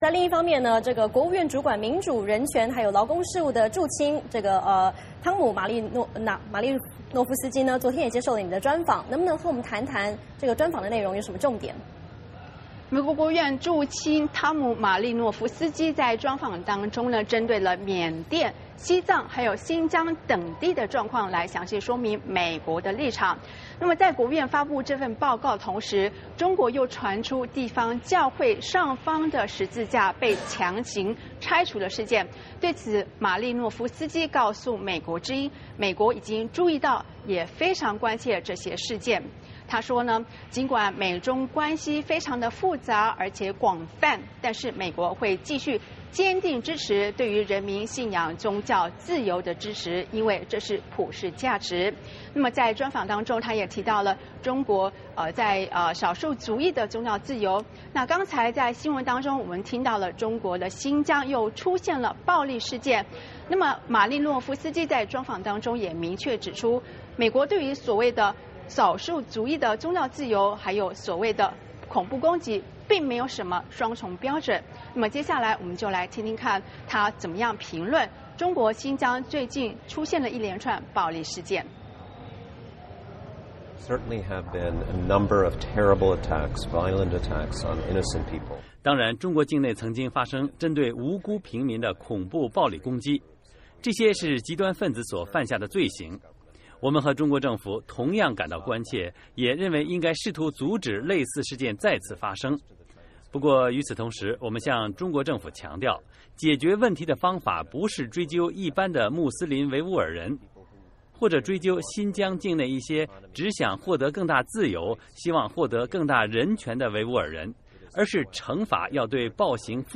VOA连线：专访国务院民主、人权、劳工事务助卿
国务院助卿马利诺夫斯基星期一接受美国之音专访，就中国境内少数族裔的宗教自由，详细阐述美国的立场。